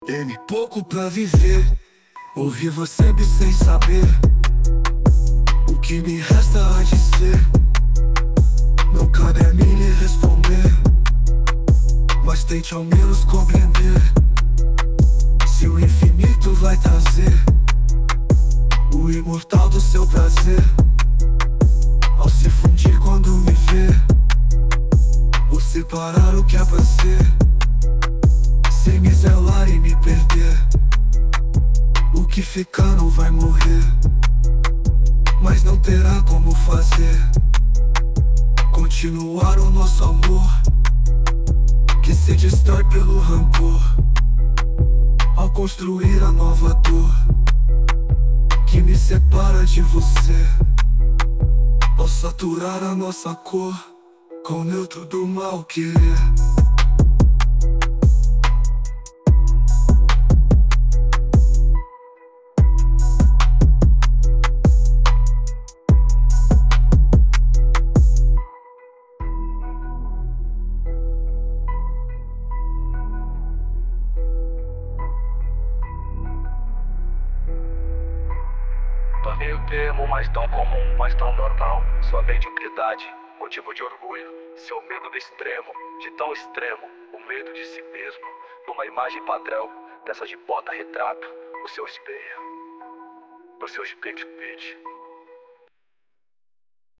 EstiloExperimental